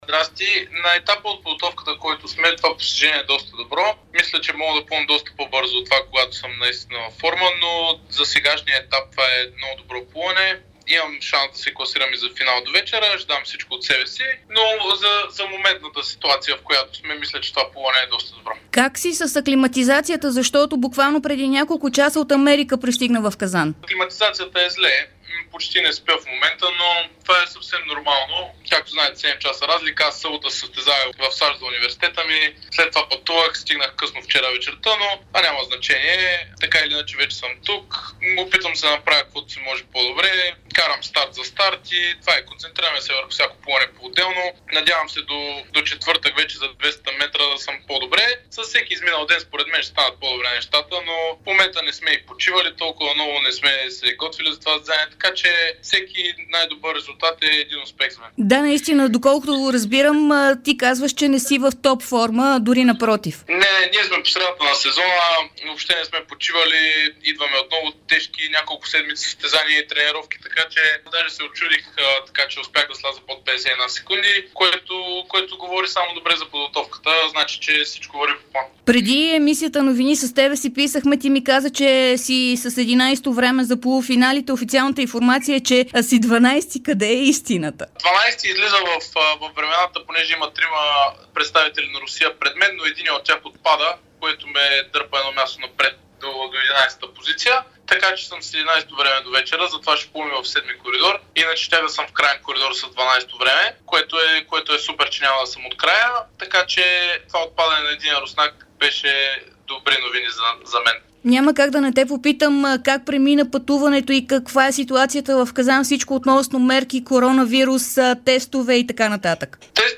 Българският плувец Антъни Иванов говори пред Дарик и dsport след класирането си на полуфиналите на 100 метра бътерфлай на европейското на 25-метров басейн в Казан.